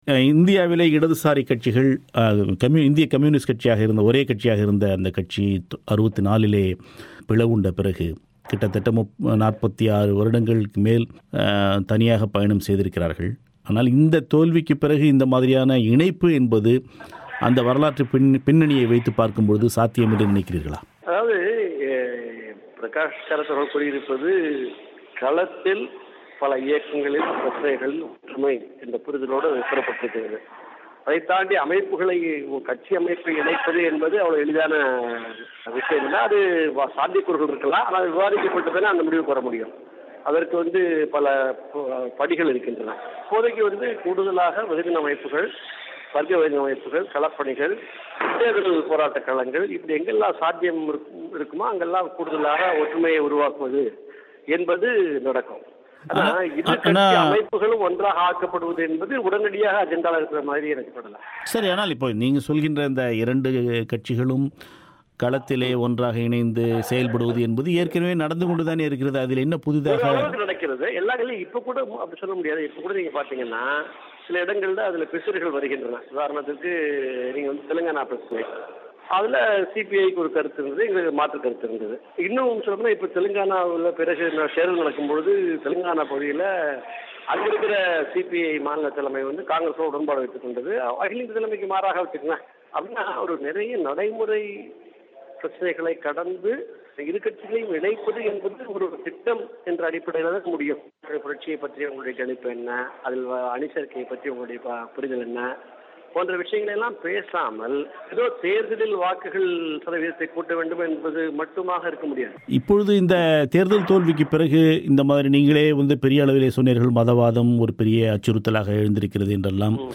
இந்திய நாடாளுமன்றத் தேர்தல் தோல்விக்குப் பிறகு இரு கம்யூனிஸ்ட் கட்சிகளும் இணைய வேண்டும் என்ற கோரிக்கைகள் எழுந்திருக்கின்றன. 1964ல் பிளவுண்ட கம்யூனிஸ்ட் இயக்கம் மீண்டும் ஒன்றாவது சாத்தியமா ? பதிலளிக்கிறார் இடதுசாரிப் பகுப்பாய்வாளர்